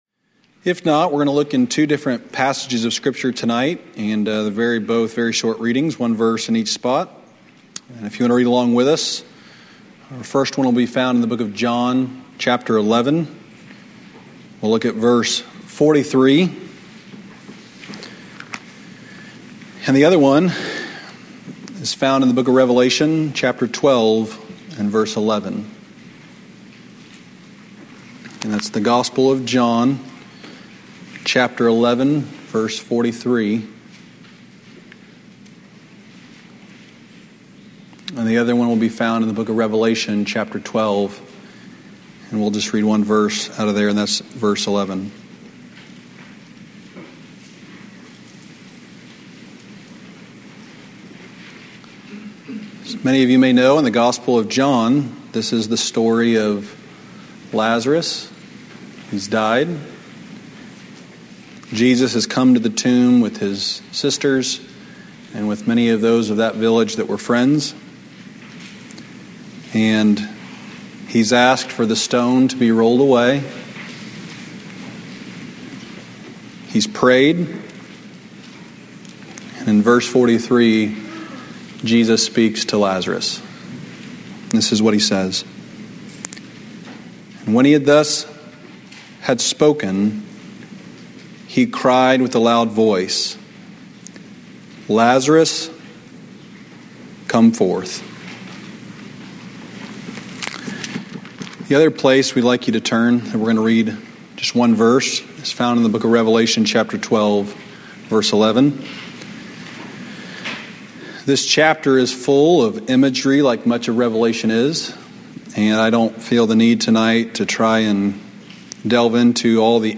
2012 Old Union Ministers School Day 2 Devotional